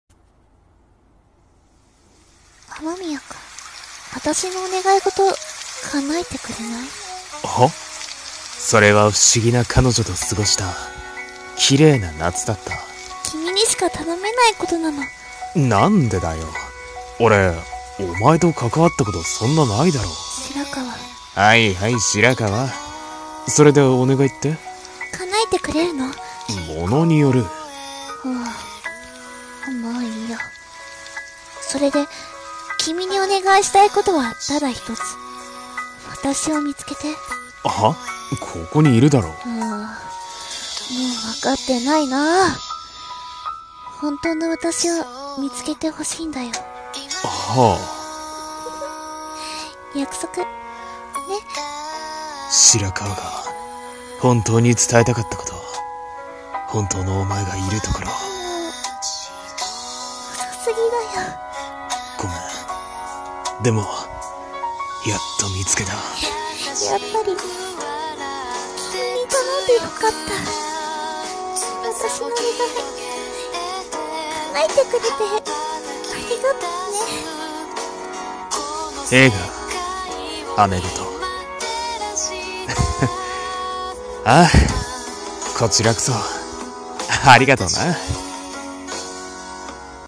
【映画予告風】